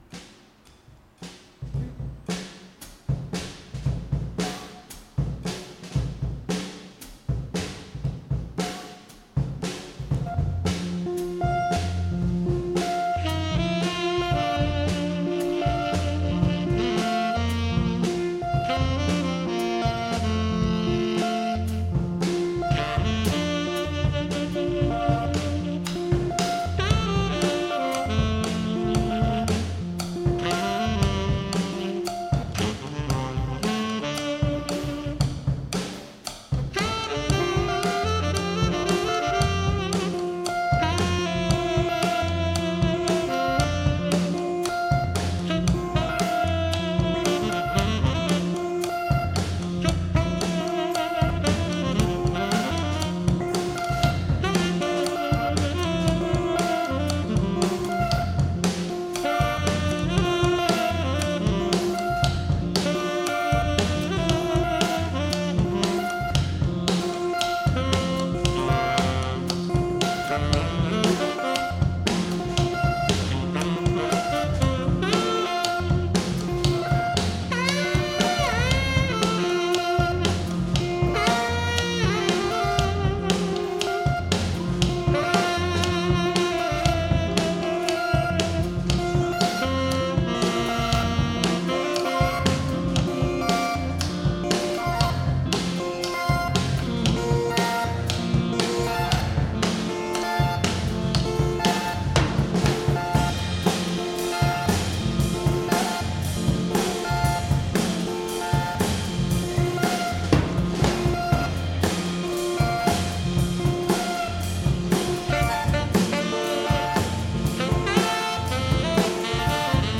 Recorded live at the 39th Street loft, Brooklyn.
drums, vocal
alto saxophone with mechanism
tenor saxophone, voice
Stereo (Metric Halo / Pro Tools)